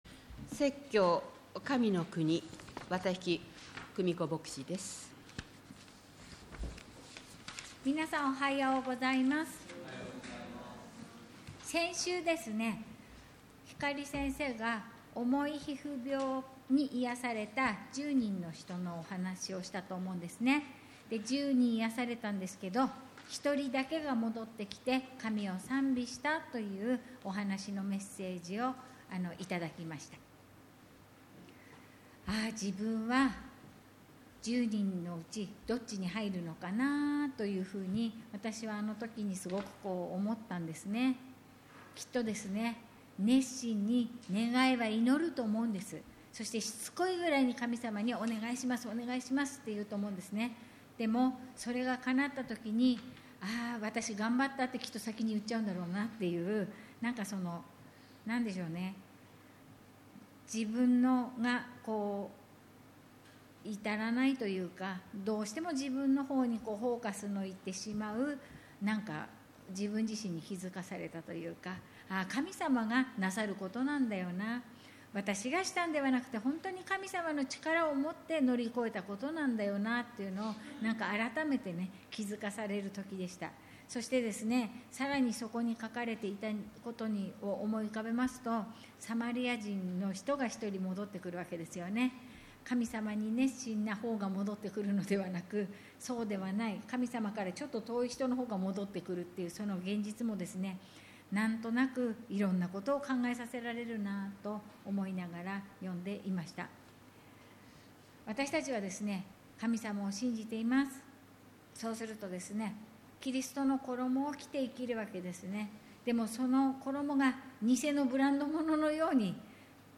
牧師